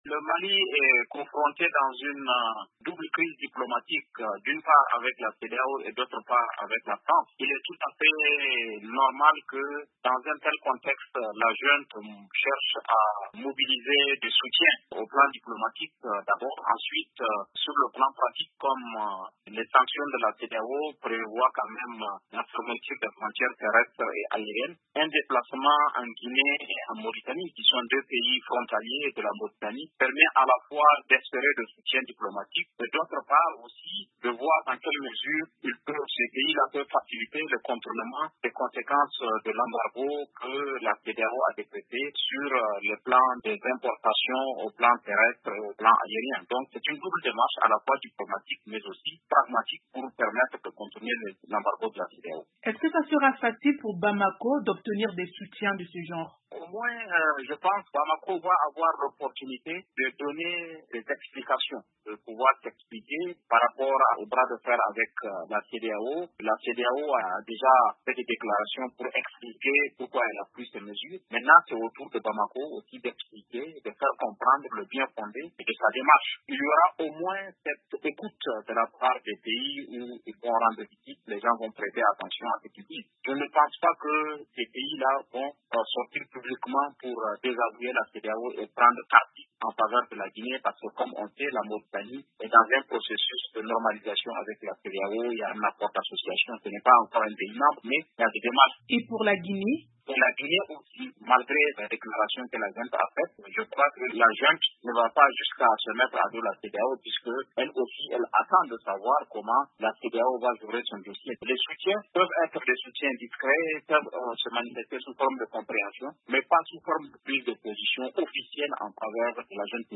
Les sanctions contre le Mali seront-elles efficaces? La réponse d'un expert